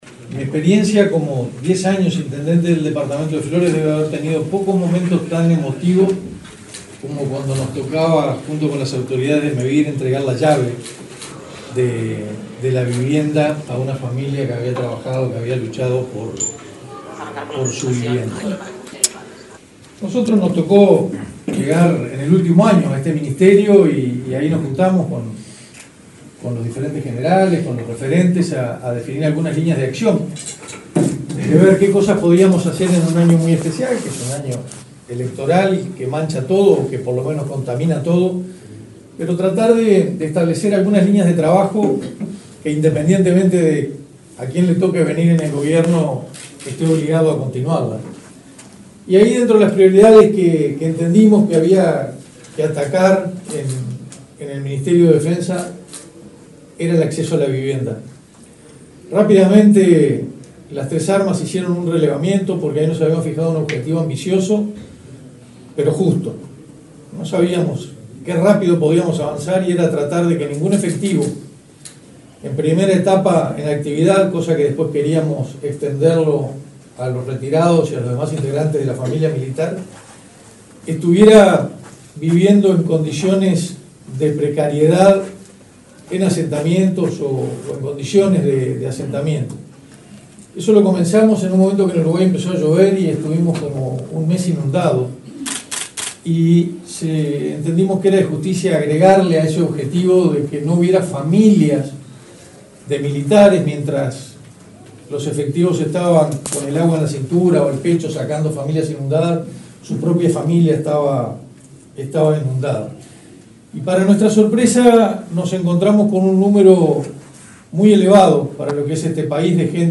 Palabras del ministro de Defensa Nacional, Armando Castaingdebat